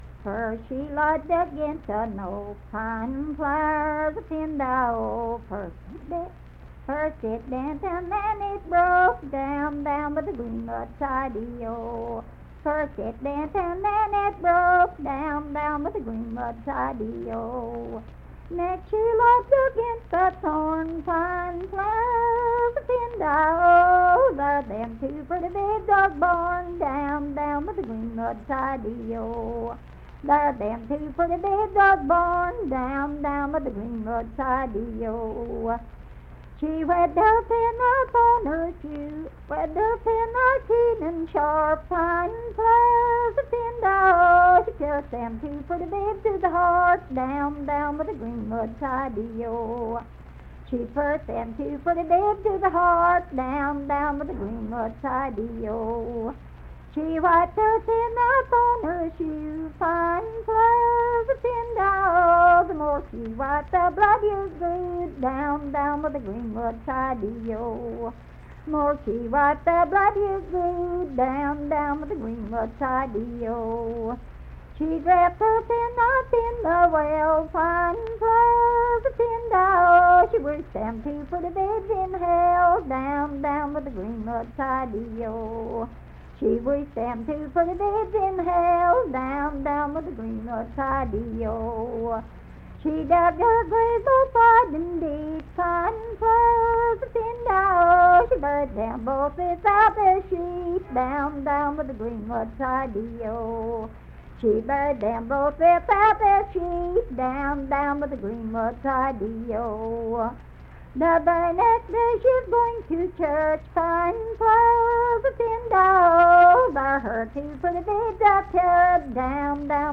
Unaccompanied vocal music
Verse-refrain, 10(6w/R).
Voice (sung)
Logan County (W. Va.)